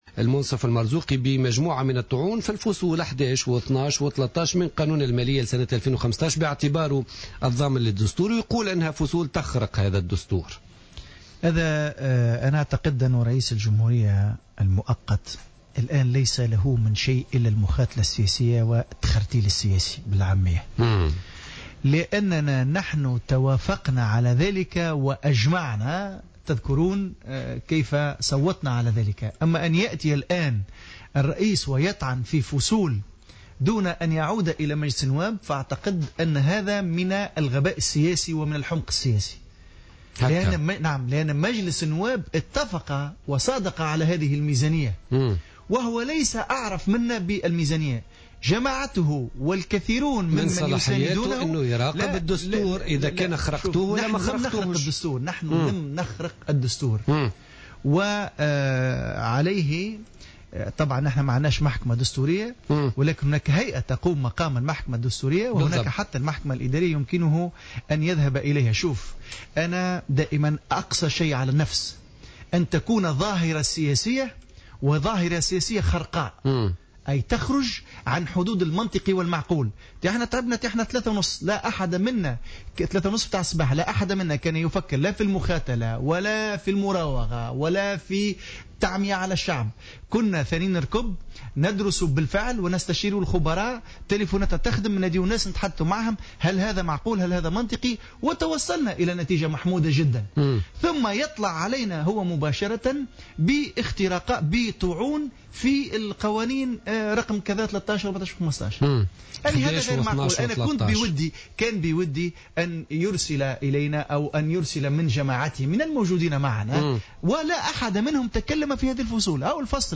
قال التوهامي العبدولي رئيس حزب الحركة الوطنية الداعم للمترشح الباجي قايد السبسي ضيف بوليتيكا اليوم الثلاثاء 16 ديسمبر 2014 في تعليق على الطعون التي قدمها رئيس الجمهورية في قانون المالية لسنة 2015 هو من قبيل الغباء والتخرتيل السياسي على حد قوله.